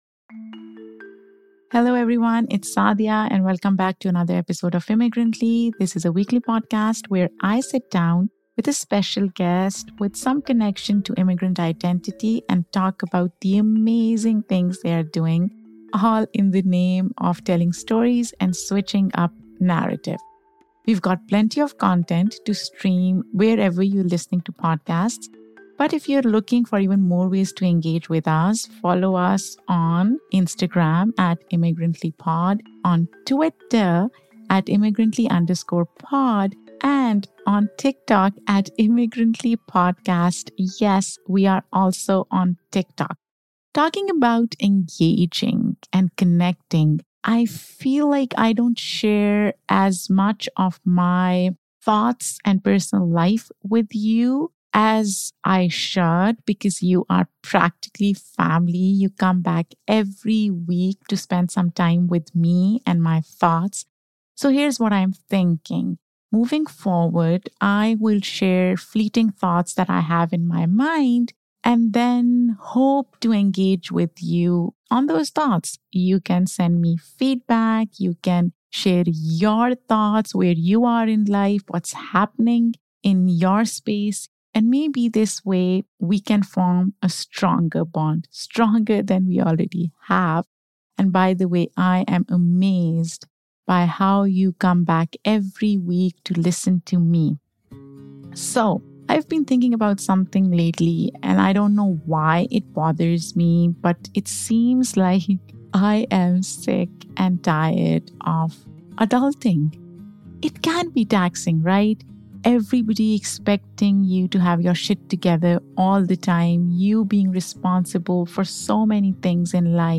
So, what brought these two together to create a great podcast for you to tune into? We find out during this conversation!